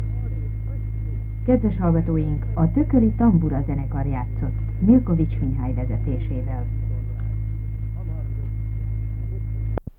Theme: Instrumental and instrumental-sung music
Announcement